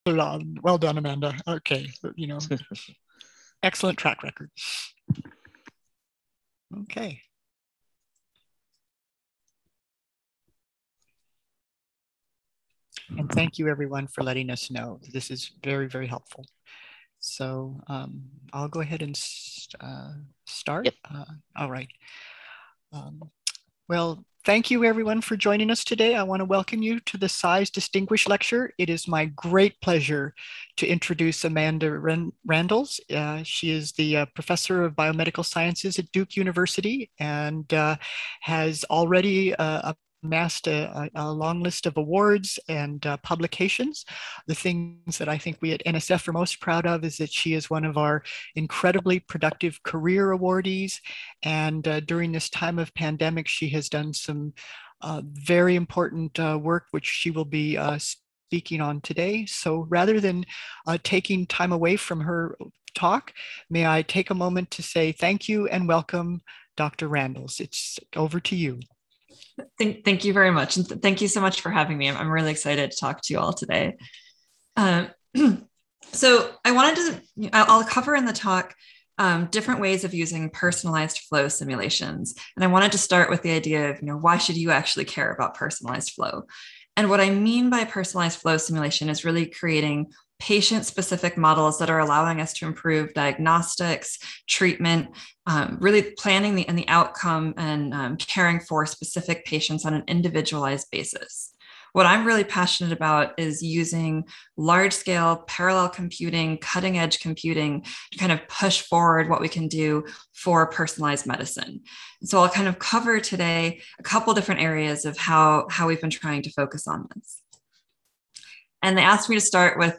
CISE Distinguished Lecture Series